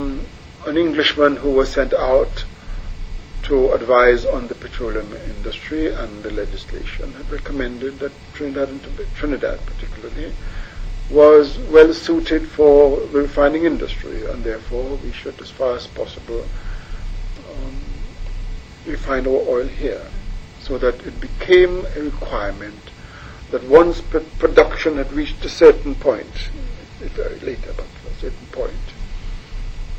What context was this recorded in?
6 audio cassettes